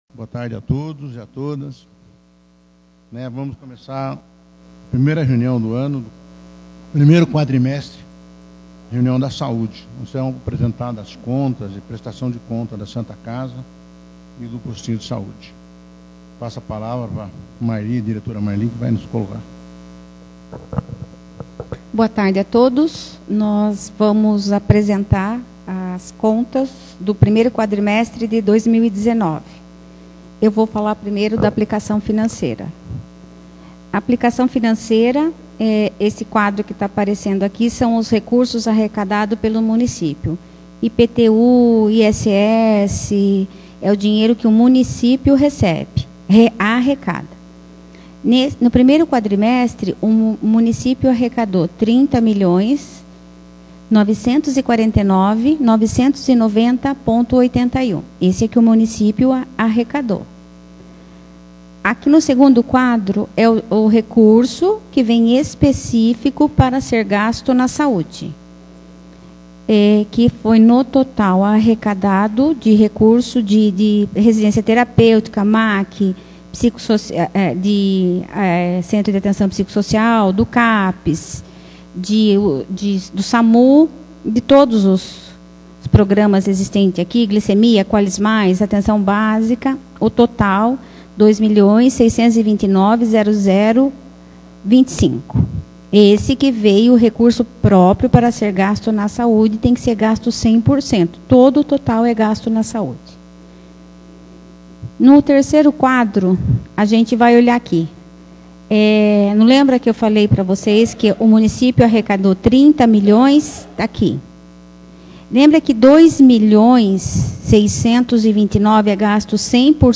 Audiência Pública da Saúde referente ao 1° quadrimestre de 2019